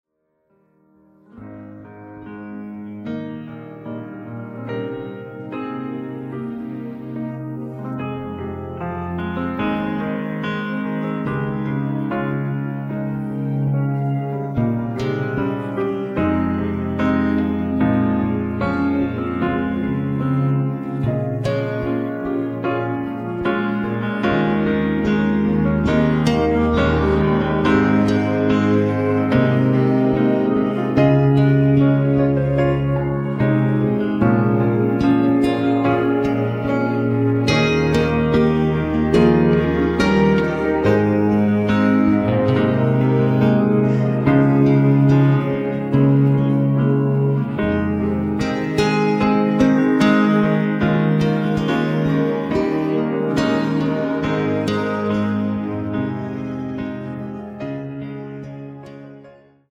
음정 원키 4:17
장르 가요 구분 Voice Cut